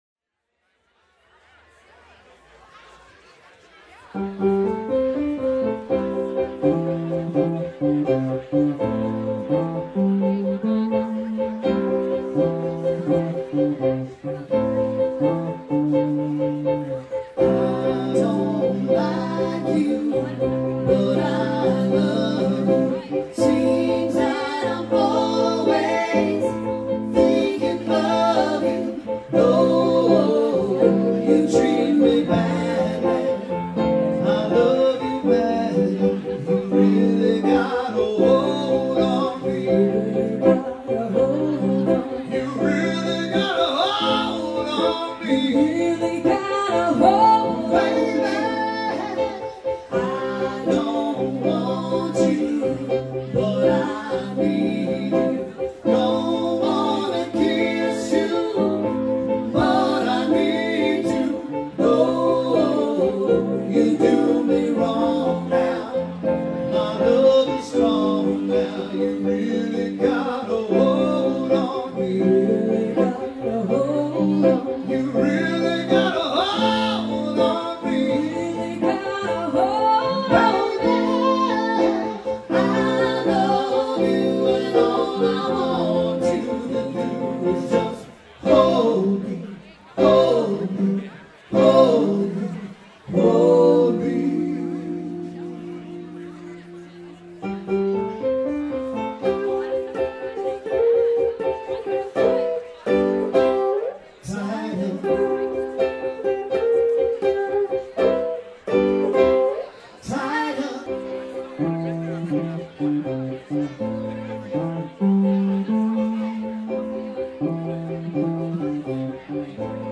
piano and vocals
guitar and vocals